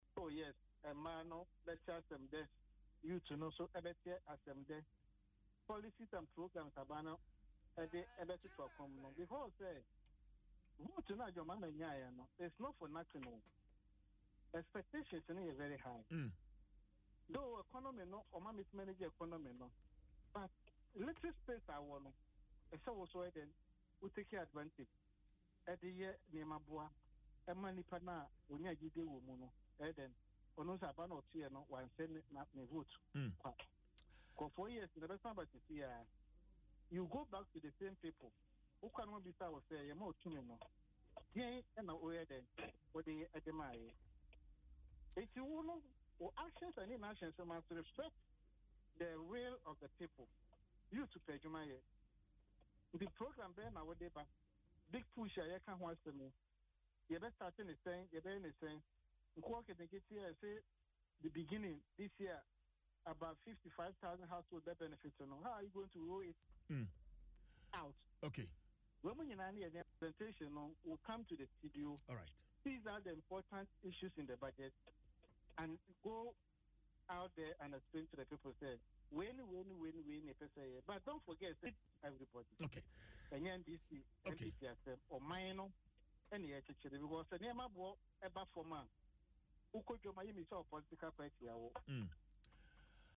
Speaking in an interview on Adom FM’s Dwaso Nsem, the MP expressed confidence that the budget will contain promising policies and programs that will benefit various groups, including the youth, women, and the business community.